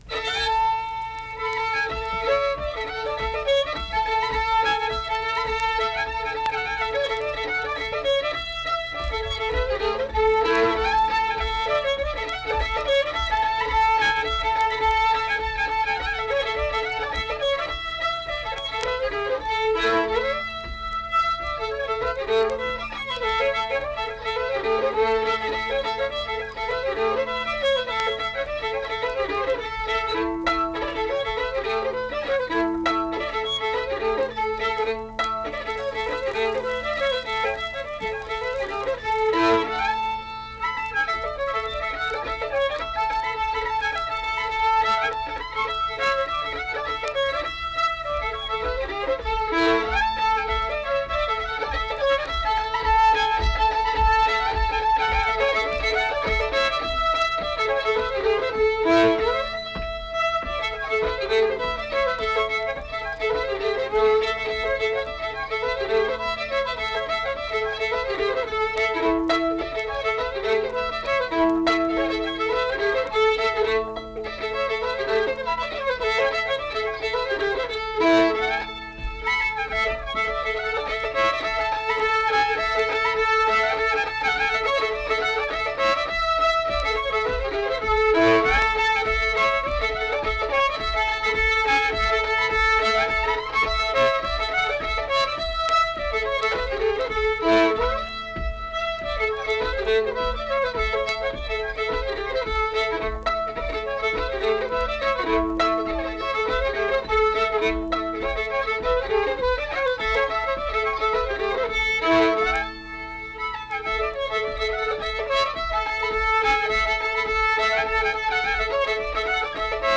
Accompanied guitar and unaccompanied fiddle music performance
Instrumental Music
Fiddle
Mill Point (W. Va.), Pocahontas County (W. Va.)